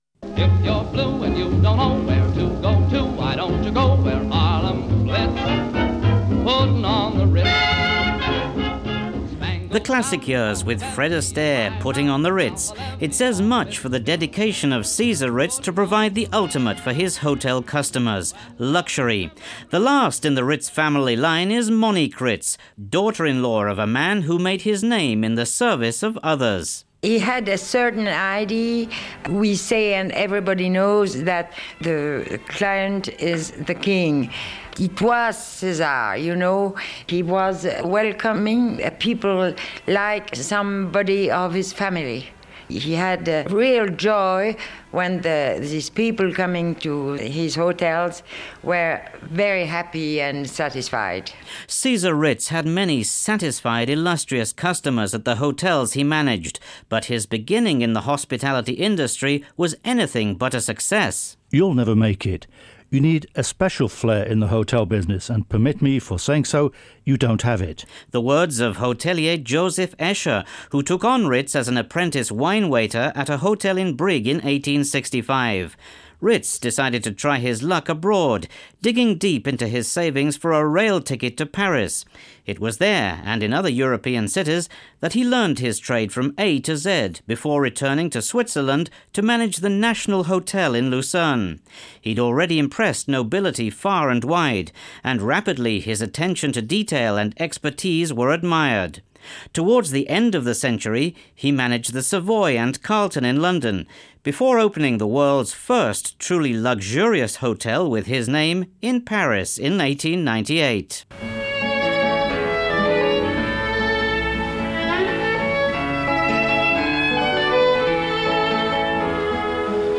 César Ritz report